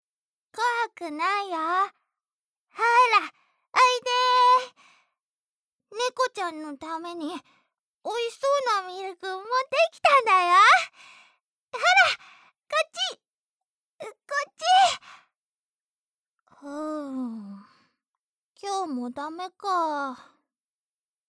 ボイスサンプル
幼い女の子